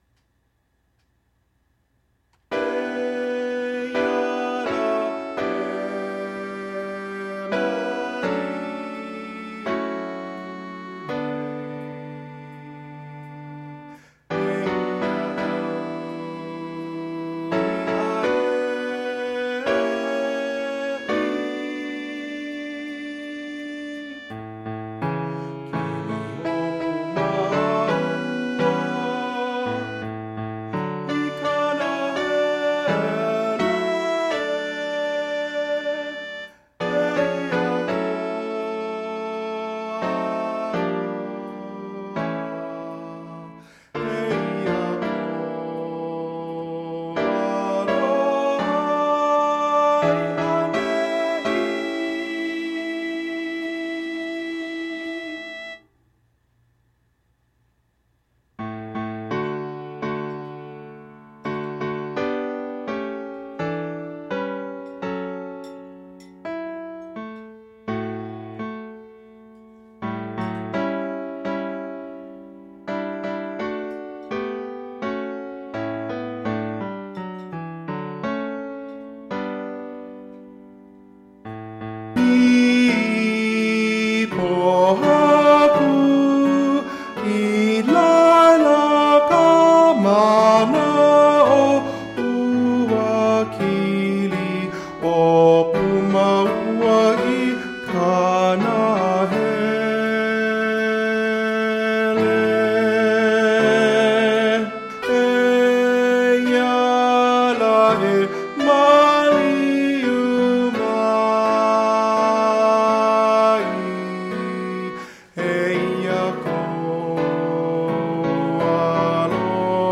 Soprano   Instrumental | Downloadable